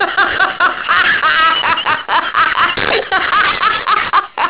] The Joker laughs